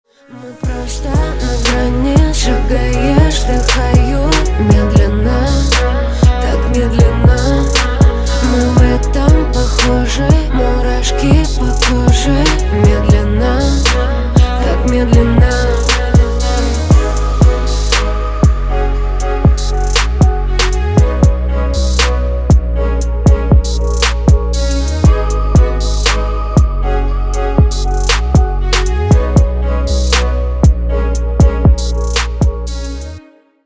• Качество: 192, Stereo
поп
спокойные
чувственные
медляк